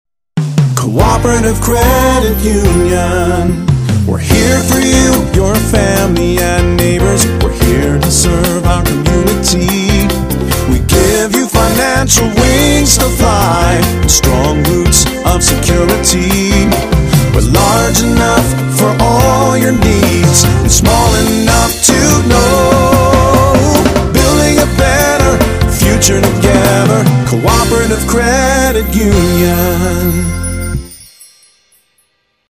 Tags: Jingle Music Marketing Musical Image Branding